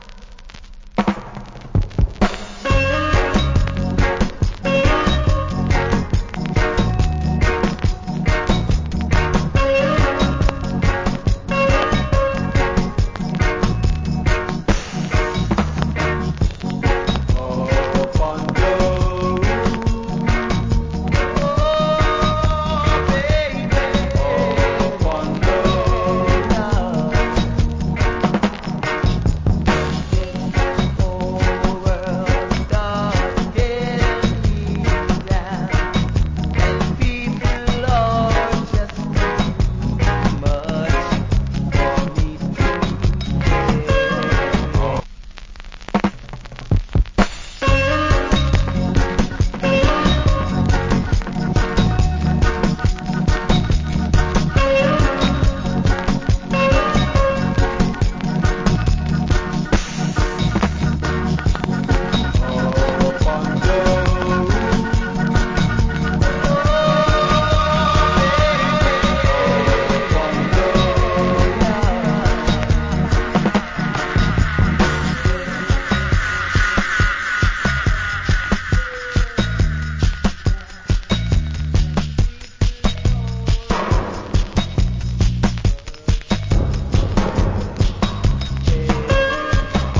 Nice Reggae Vocal. Cover.